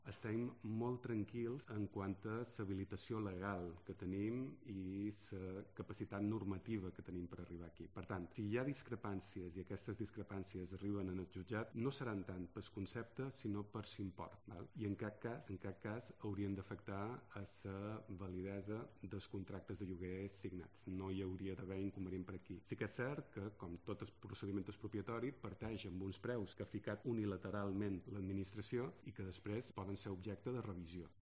El director general de vivienda, Eduardo Robsy